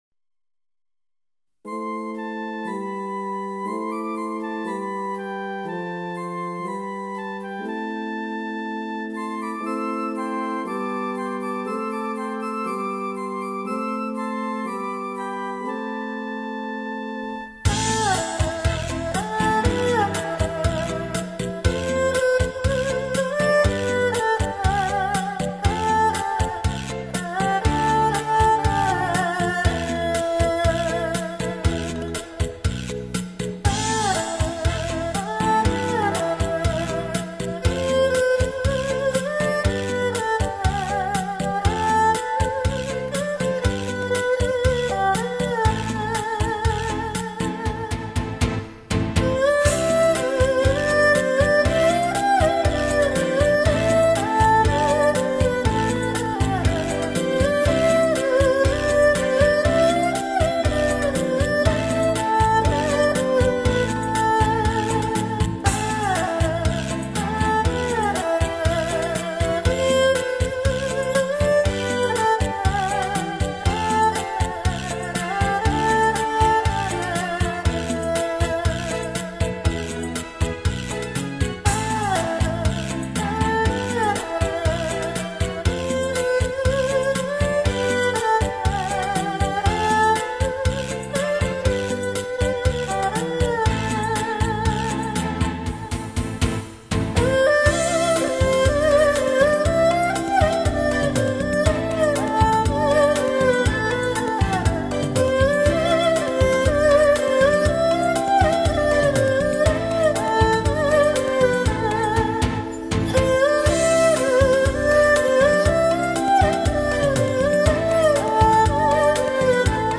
恰恰舞曲